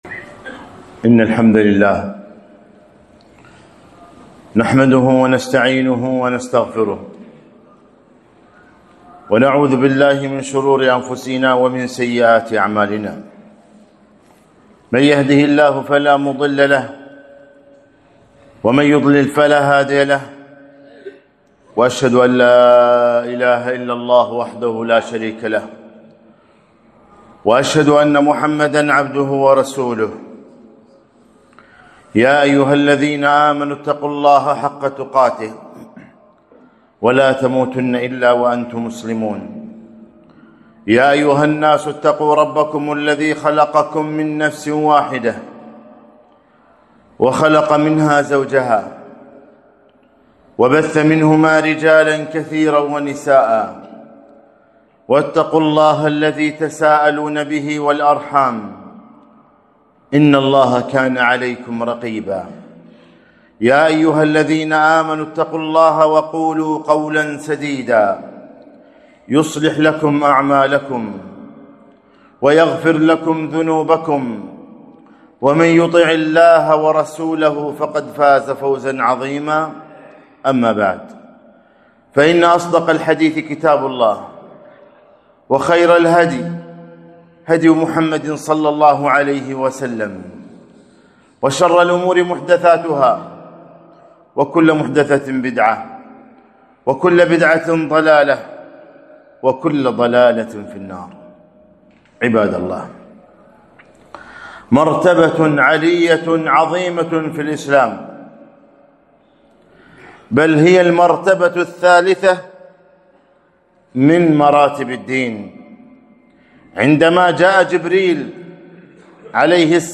خطبة - إن الله يحب المحسنين